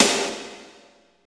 52.02 SNR.wav